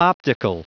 Prononciation du mot optical en anglais (fichier audio)